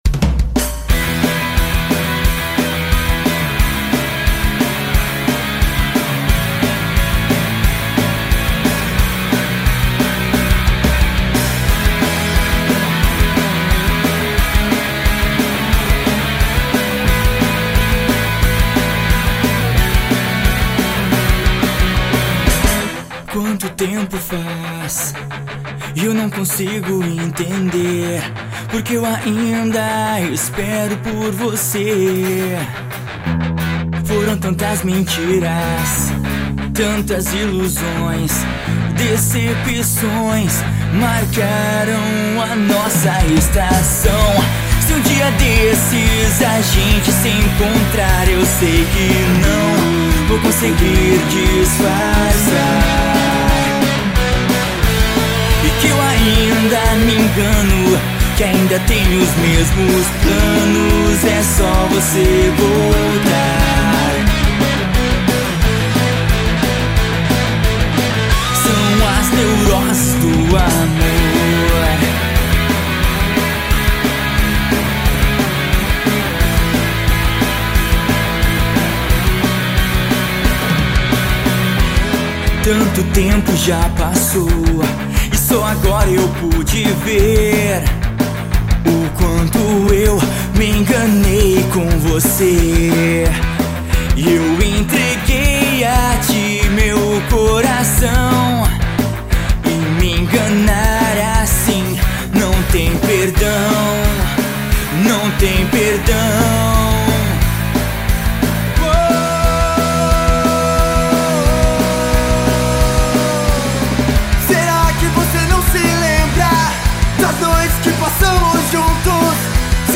EstiloEmocore